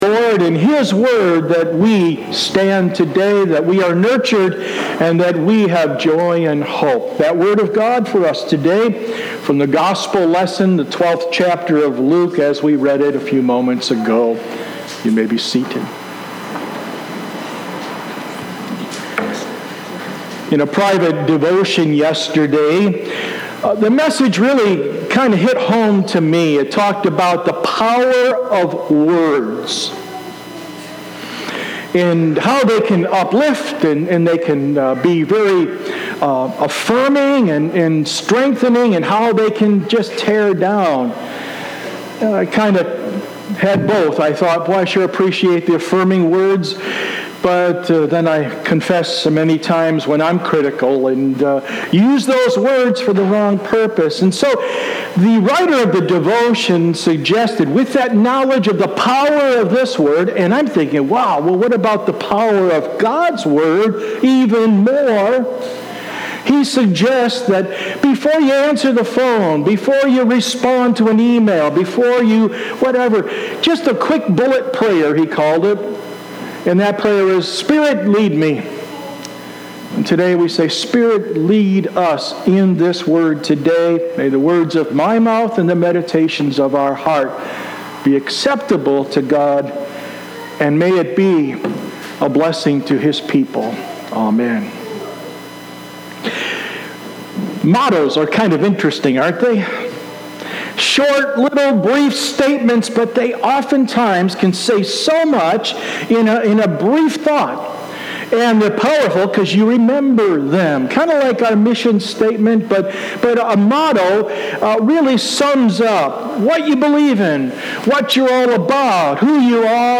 Sermon 8-4-19
Sermon-8_4.mp3